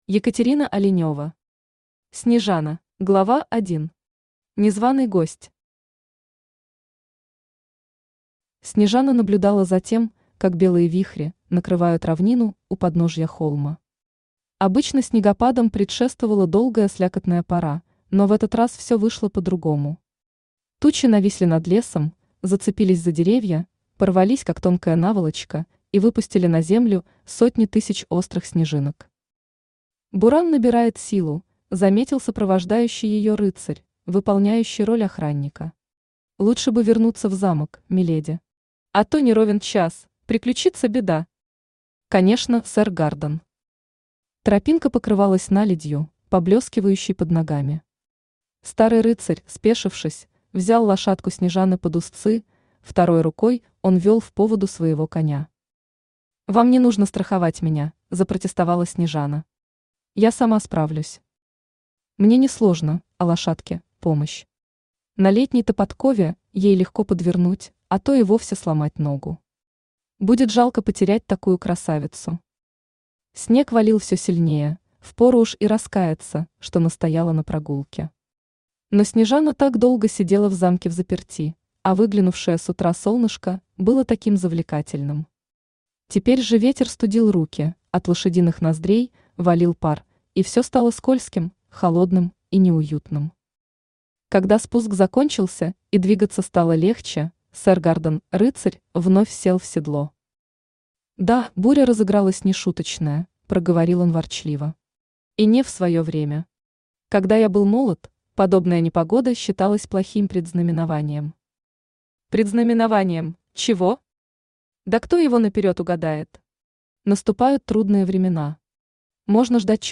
Аудиокнига Снежана | Библиотека аудиокниг
Aудиокнига Снежана Автор Екатерина Оленева Читает аудиокнигу Авточтец ЛитРес.